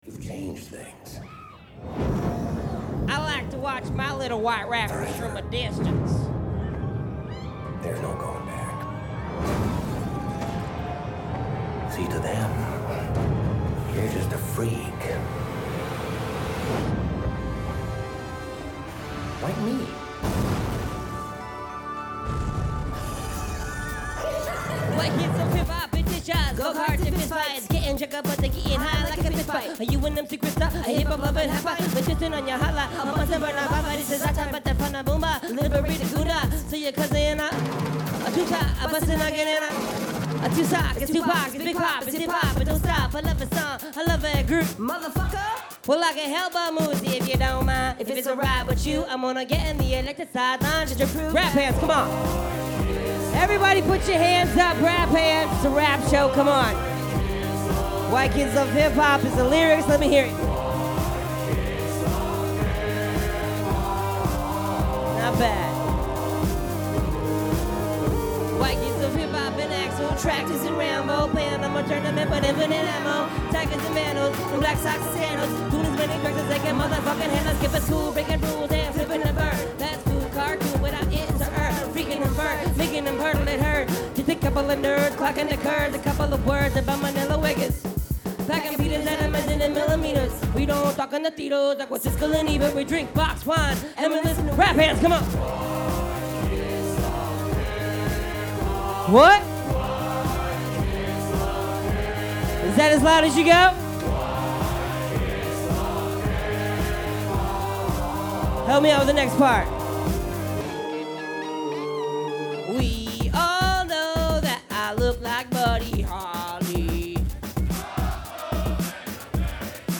bellingham, wa at the nightlight lounge on october 21st 2009
live at the nightlight lounge, soundboard recording